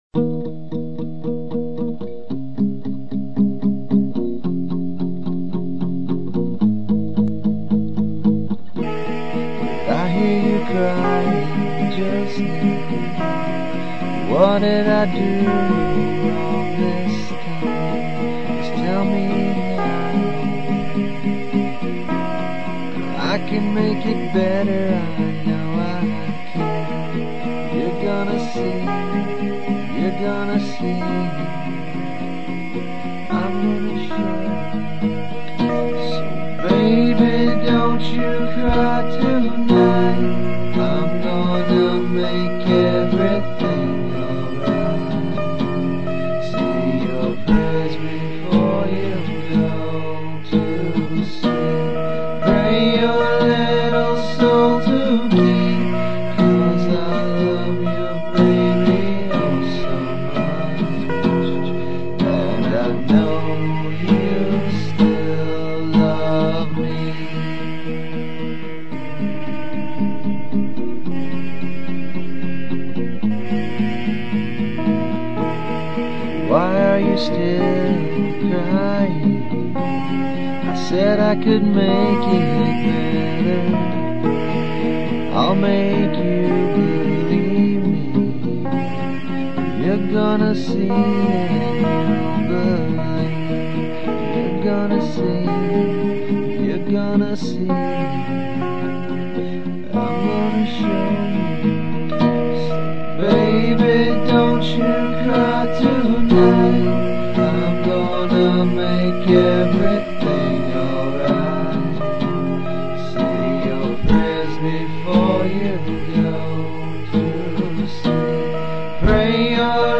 Additional Guitar & Effects